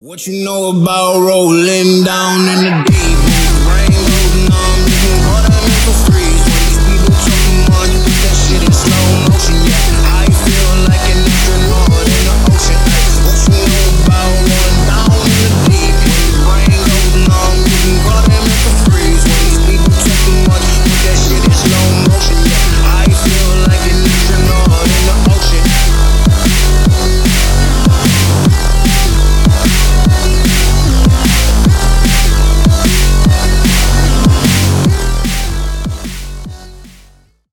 Ремикс # Поп Музыка
громкие